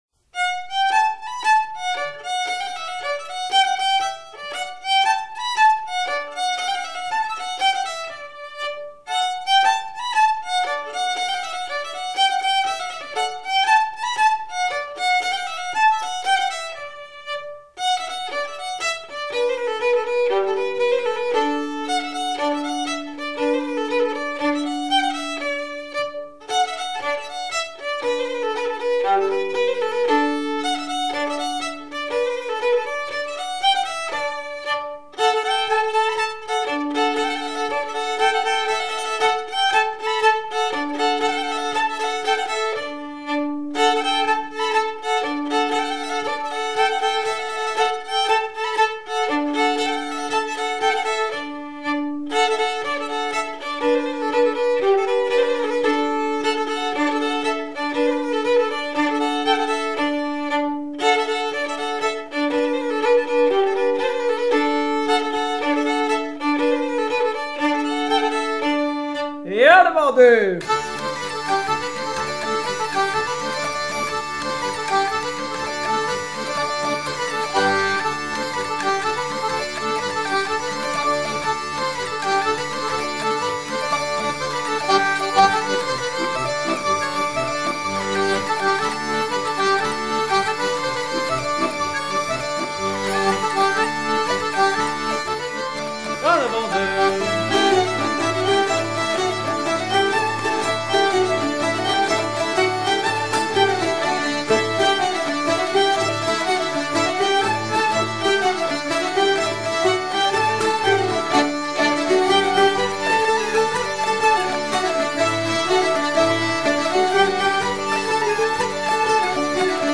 danse : branle : avant-deux
Pièce musicale éditée